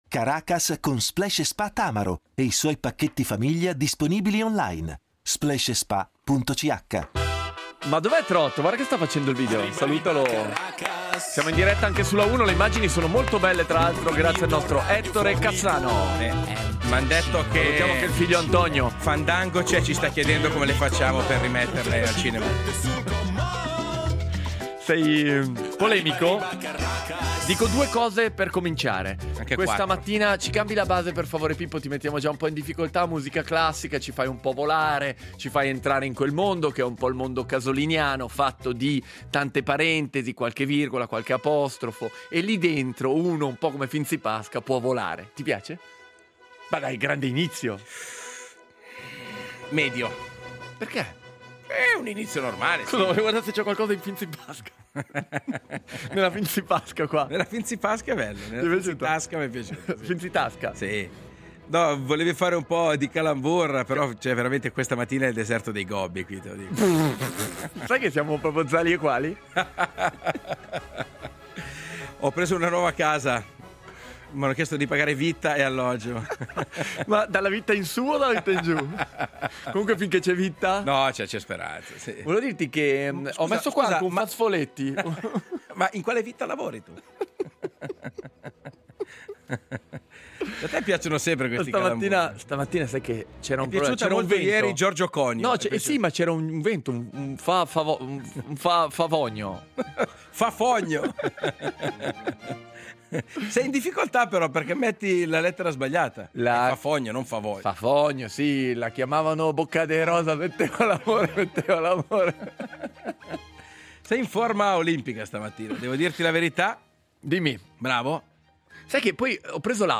Il duo parte in maniera frizzantina con una serie di freddure e giochi di parole che spaziano (e spiazzano) dai politici all’arte.